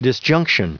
Prononciation du mot disjunction en anglais (fichier audio)
Prononciation du mot : disjunction